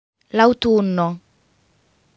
L’AUTUNNO (m.) /l’ auˈtunno/ is what you know in English as AUTUMN.